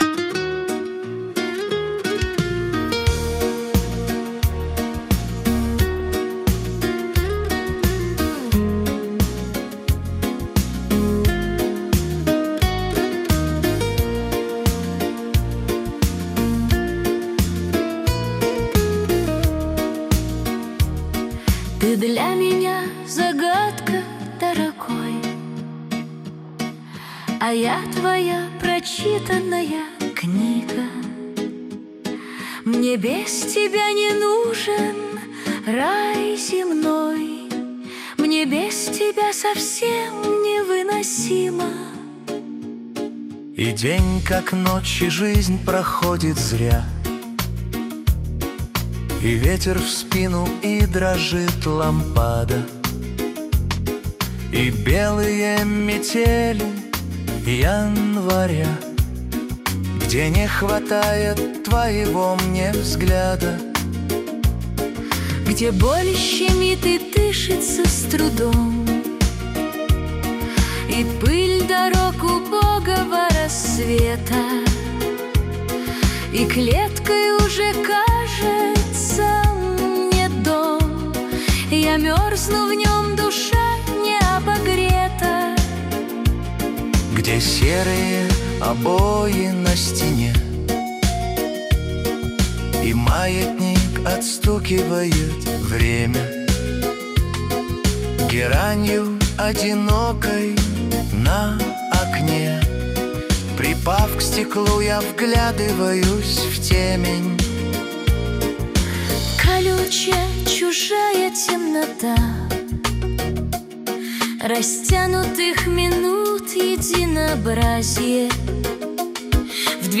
лирические песни и романсы